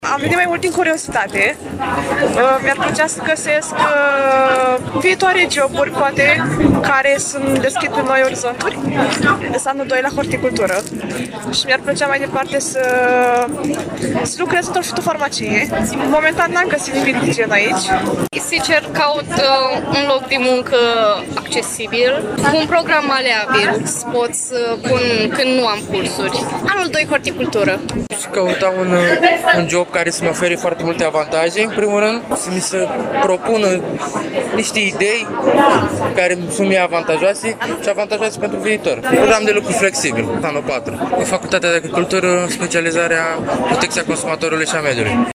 7-nov-ora-13-VOX-USAMV.mp3